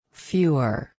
(ˈfyuər)   menos